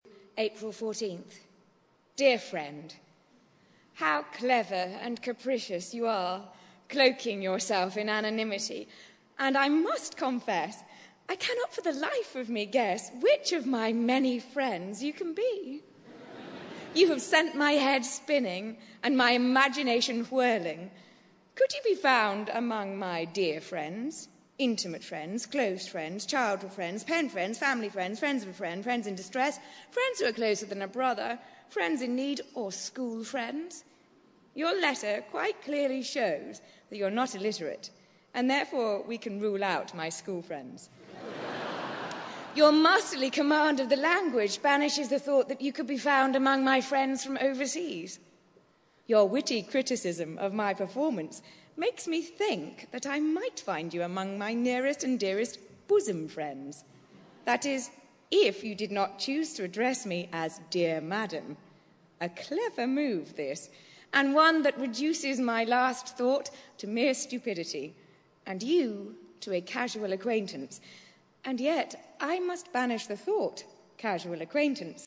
在线英语听力室见信如晤Letters Live 第23期:'露易丝·布瑞莉&索菲·亨特'读信:亲爱的朋友(2)的听力文件下载,《见信如唔 Letters Live》是英国一档书信朗读节目，旨在向向书信艺术致敬，邀请音乐、影视、文艺界的名人，如卷福、抖森等，现场朗读近一个世纪以来令人难忘的书信。